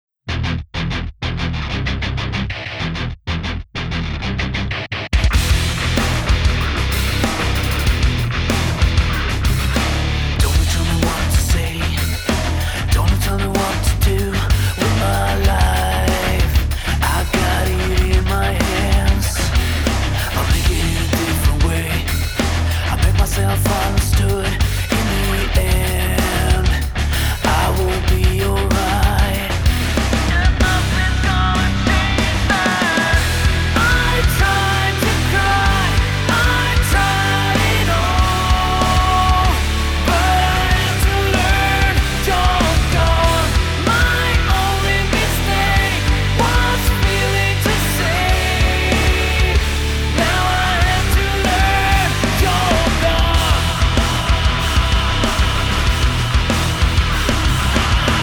• Качество: 320, Stereo
мужской вокал
громкие
Alternative Rock
Hard rock
Modern Rock